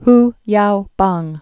(h youbäng) also Hu Yao-pang (-päng) 1915-1989.